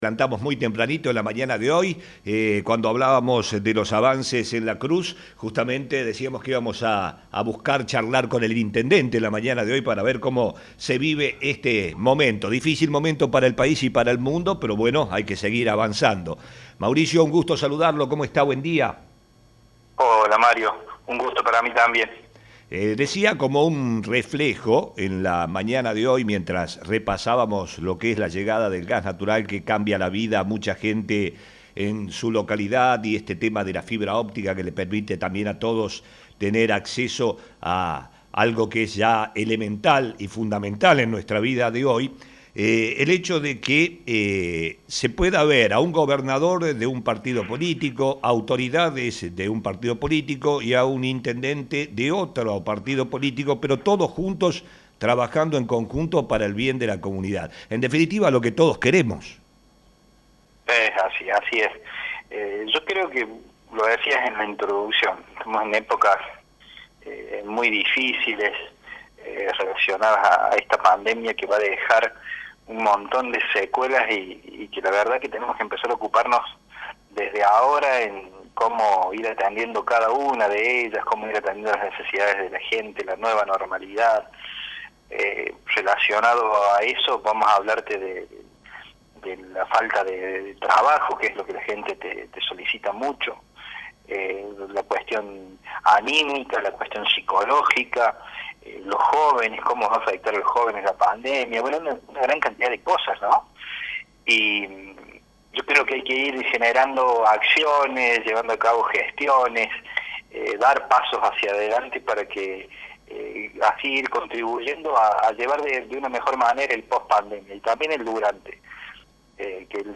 Dialogamos con el intendente Mauricio Jaimes quien destacó el esfuerzo que se hizo para lograr éstos objetivos. Jaimes dijo que se necesita avanzar trabajando conjuntamente sin distinciones partidarias porque eso reclama la sociedad.